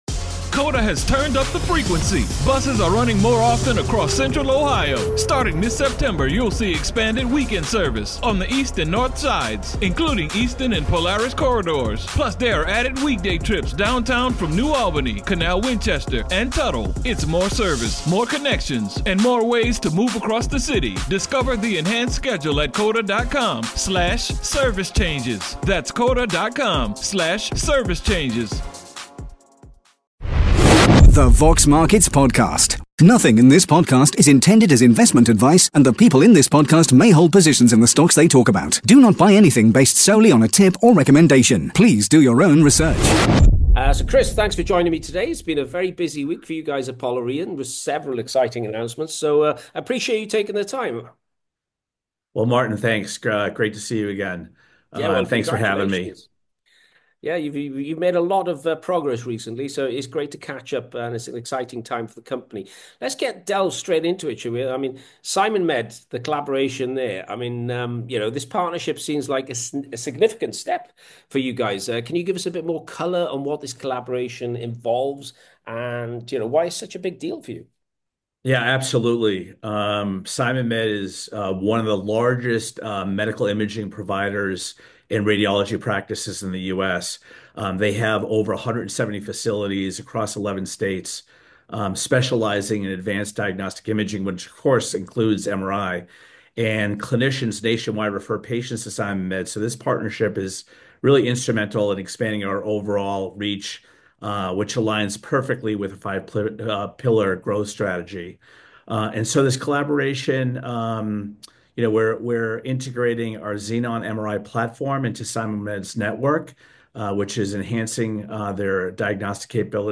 In this exclusive interview